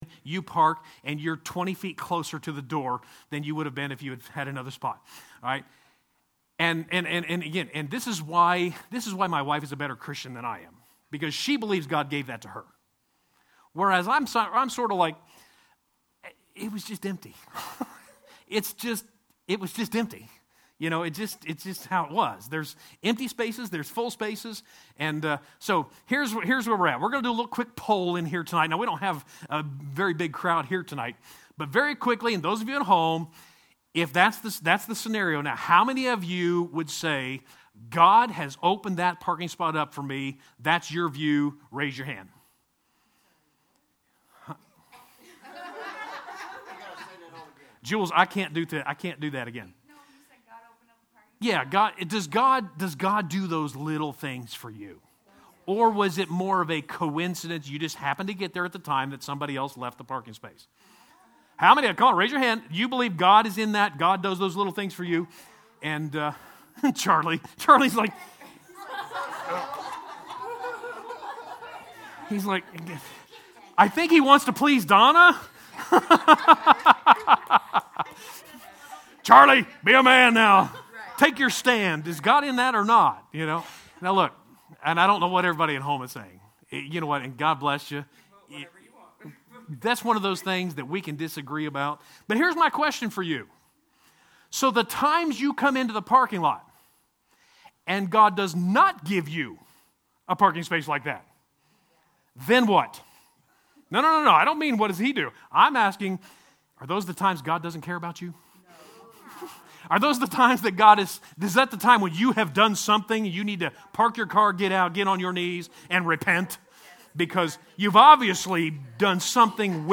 3-17-21 Wednesday Bible Study: God’s Word, God’s Work, and God’s Will #2